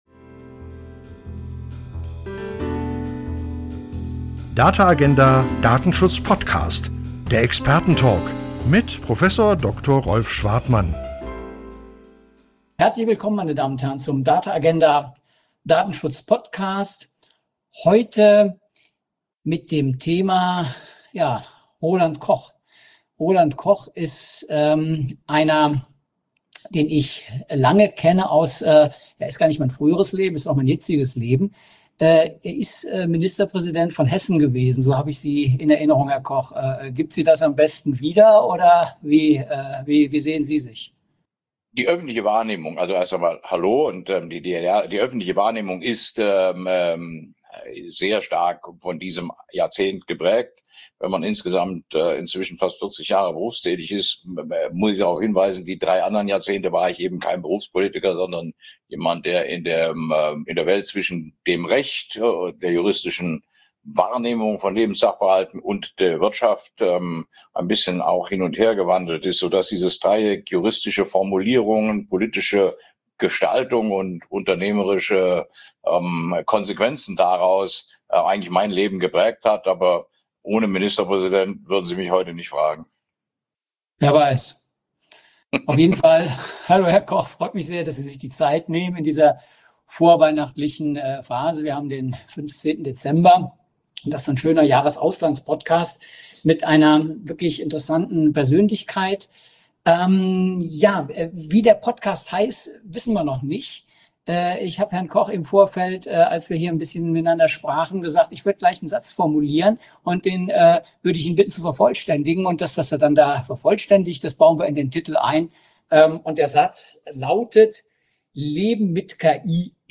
Der Datenschutz-Talk